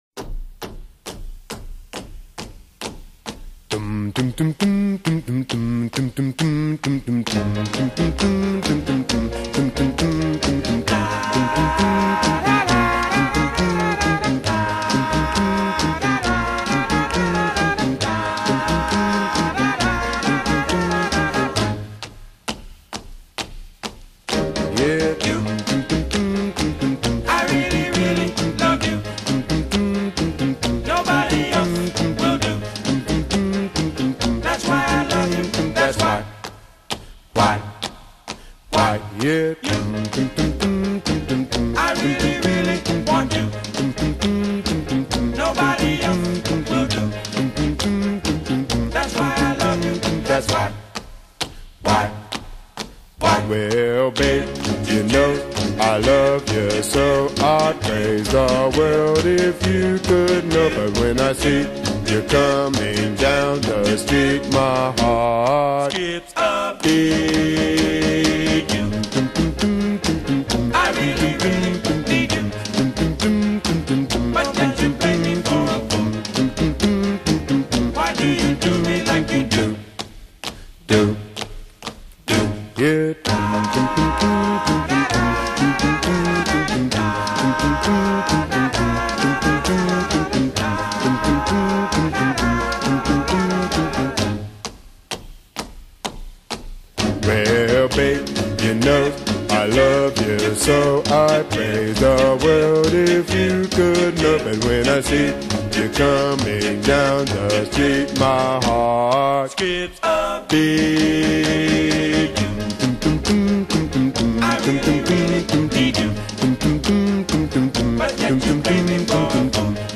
R&B group